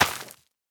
minecraft / sounds / item / plant / crop5.ogg